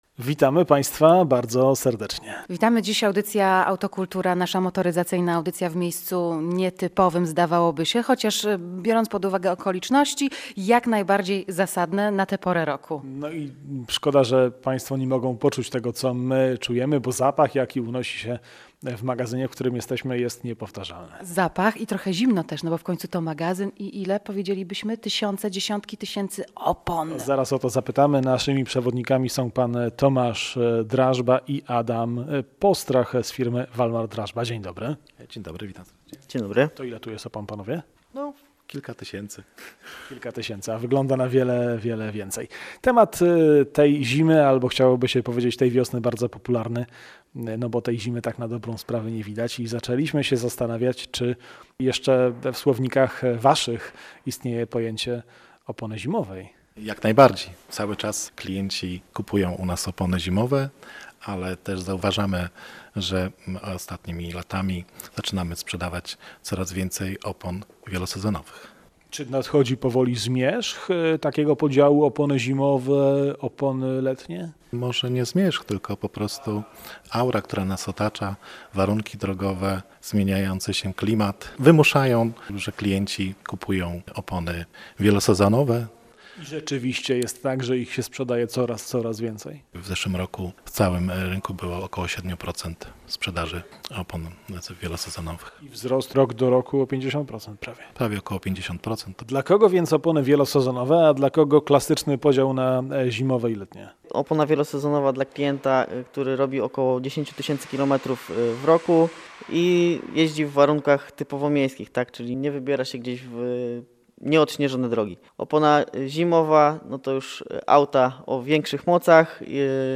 To wydanie Autocooltury prosto z hurtowni opon Walmar Drażba w Pruszczu Gdańskim.